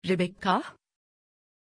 Pronunția numelui Rebekkah
pronunciation-rebekkah-tr.mp3